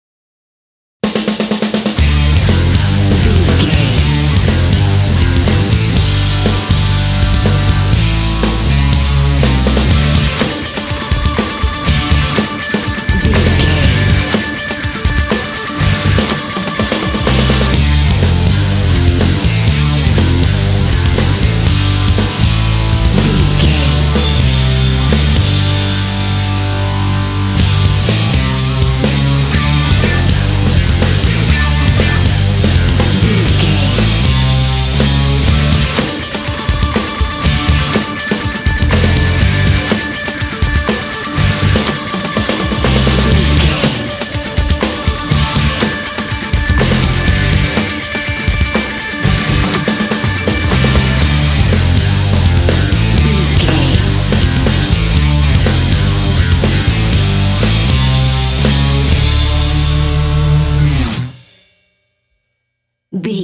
Epic / Action
Dorian
D
powerful
energetic
heavy
bass guitar
drums
electric guitar
heavy metal
alternative rock
classic rock